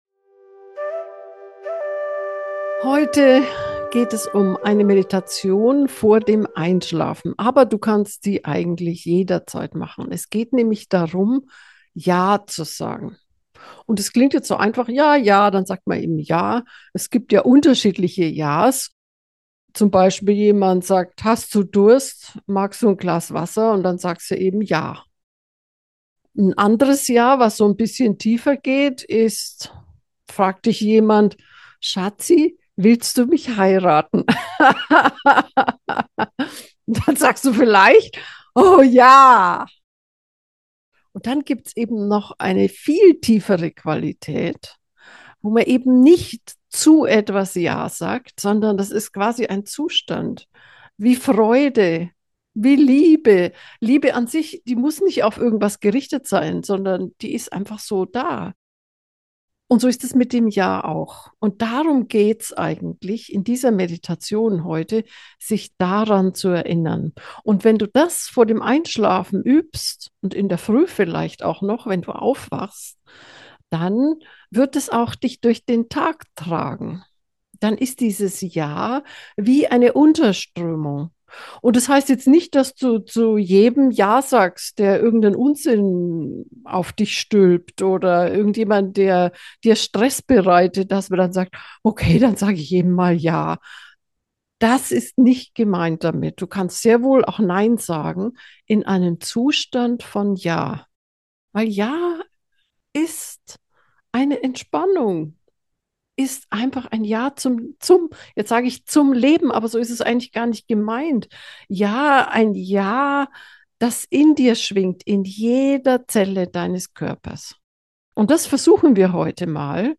Ja-sagen-einschlafmeditation.mp3